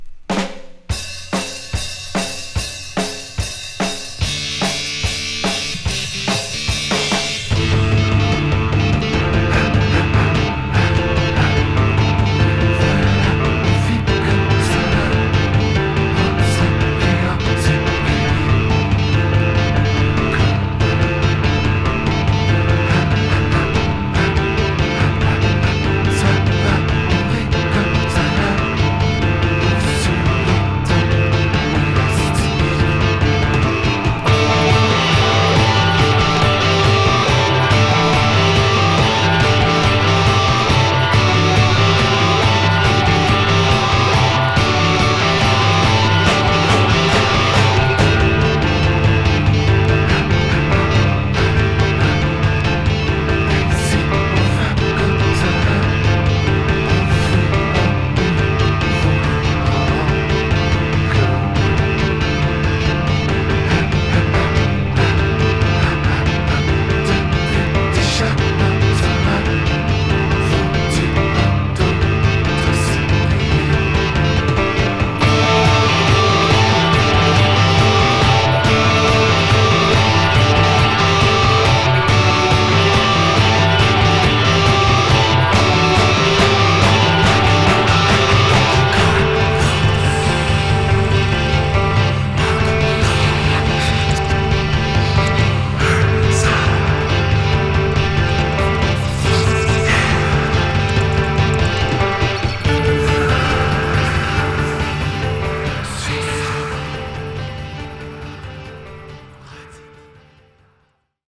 1996年にカナダ・モントリオールで結成された実験的なインストゥルメンタルロックバンドの3rd album。